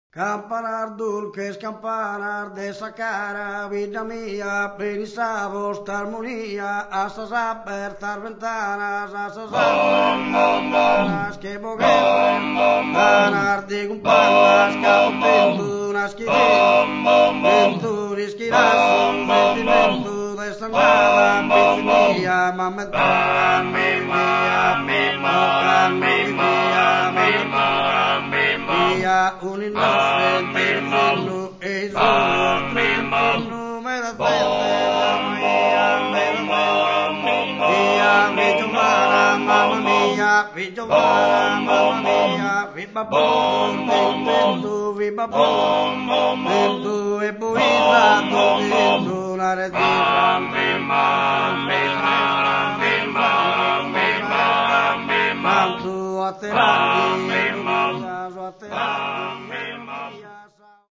Due voci gutturali potenti, una mesu boche fantasiosa e una boche forte e mai ripetitiva conferiscono al coro un timbro inconfondibile e di grande fascino, che lo ha reso famoso in tutta la Sardegna.
forme tradizionali